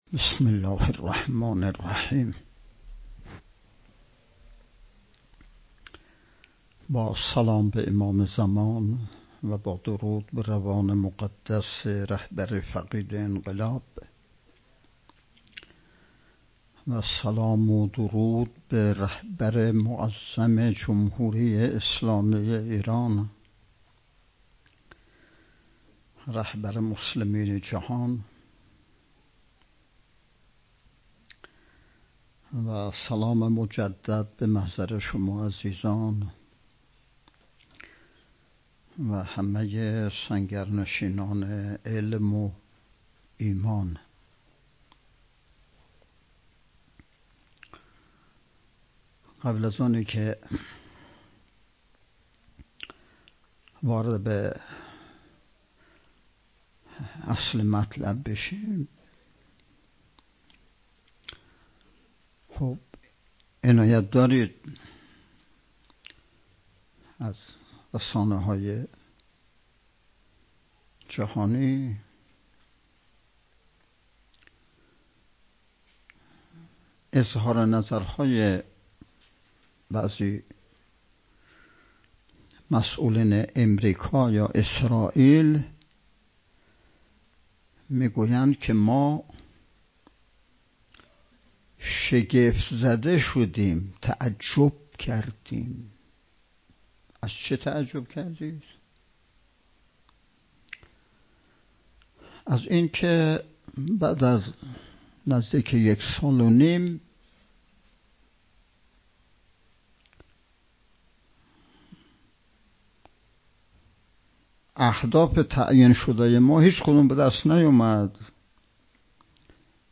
چهاردهمین نشست ارکان شبکه تربیتی صالحین بسیج با موضوع تربیت جوان مؤمن انقلابی پای کار، صبح امروز ( ۴ بهمن ) با حضور و سخنرانی نماینده ولی فقیه در استان، برگزار شد.